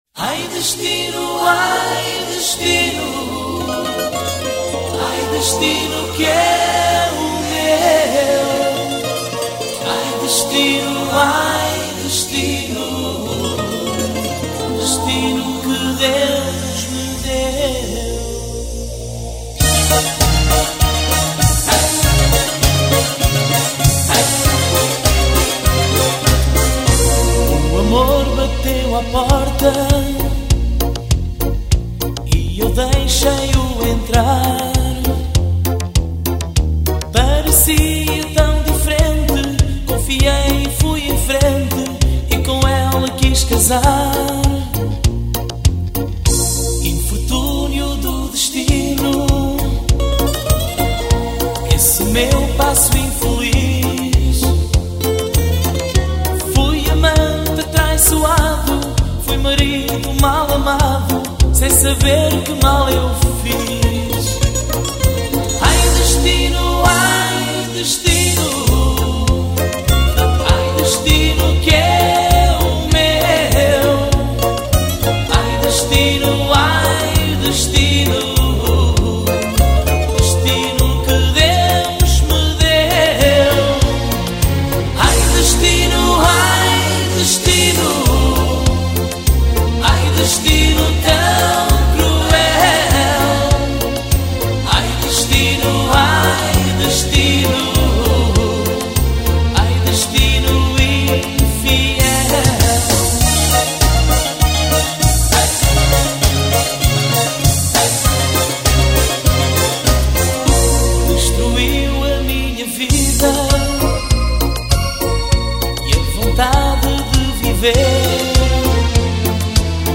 Эта песня появилась в хорошем качестве.